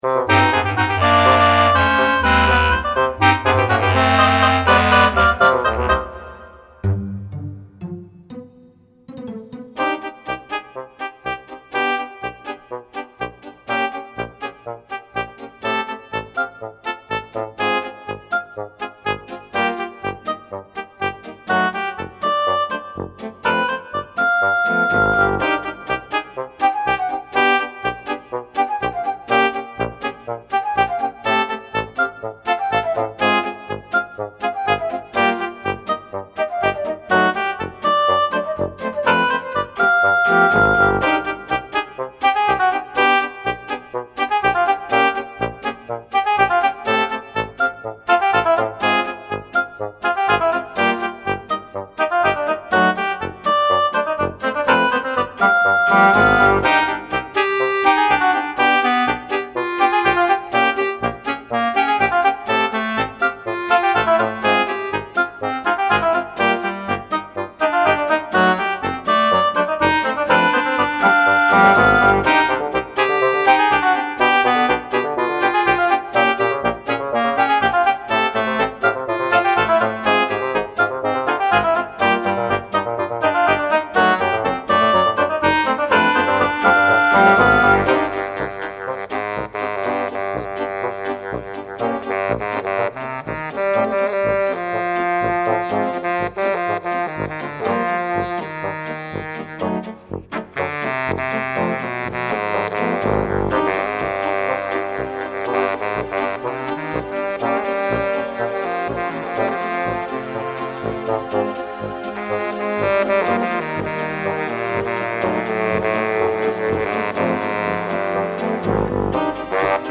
musique du film